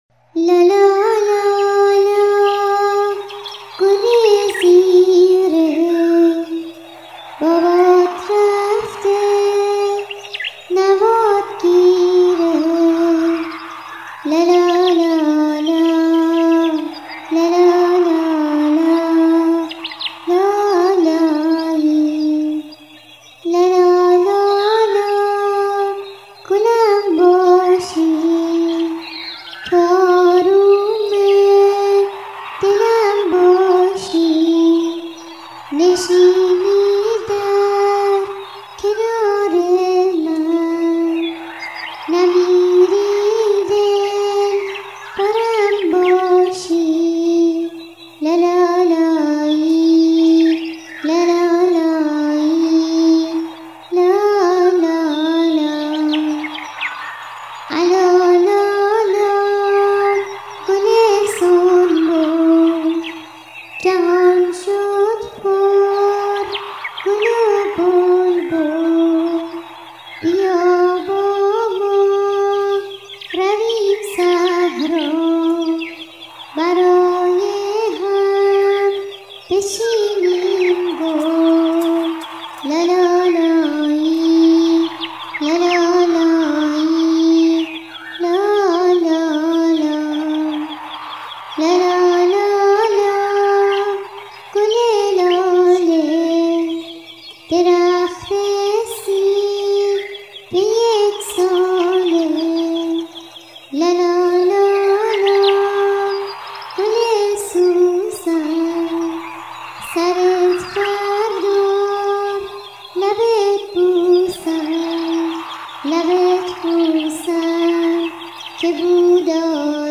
لالایی لالا لالا گل زیره
آهنگ لالایی